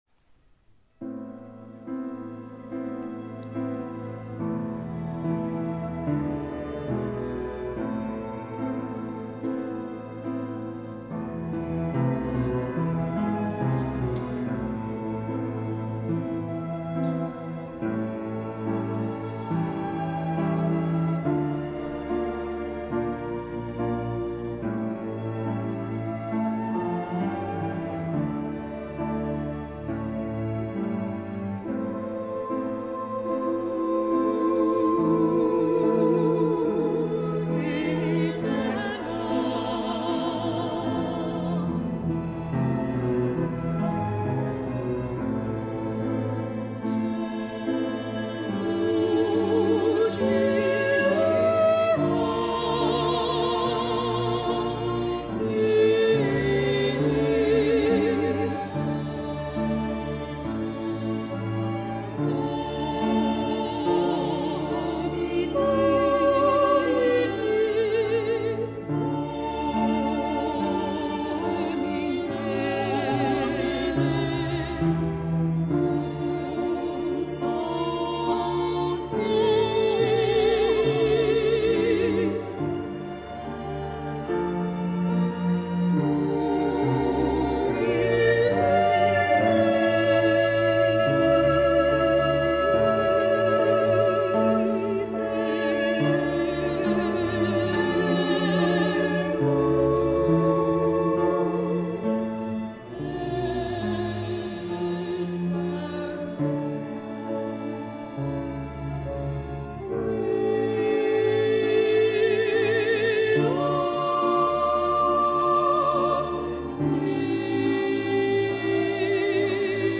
CONCERT CARHAIX DU 26 juin 2011 St BRIEUC le 22 oct et QUINTIN le 23 oct 2011